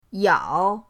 yao3.mp3